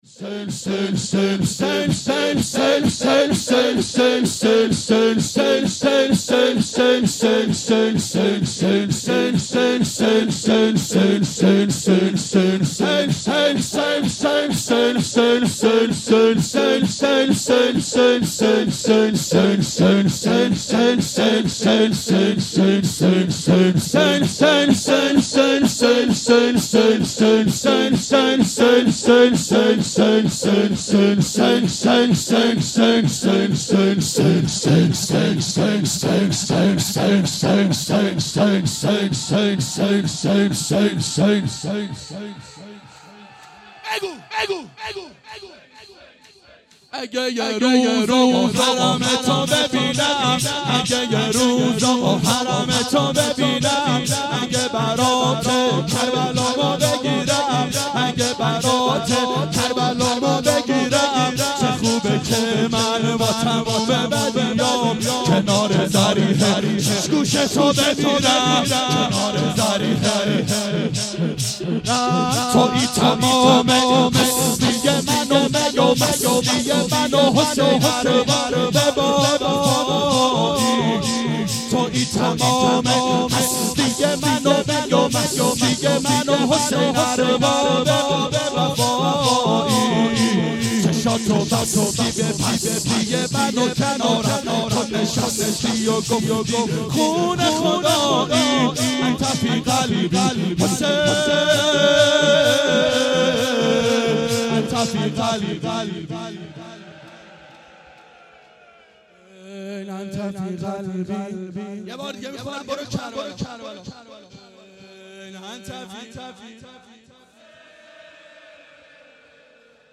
• دهه اول صفر سال 1391 هیئت شیفتگان حضرت رقیه سلام الله علیها (شب اول)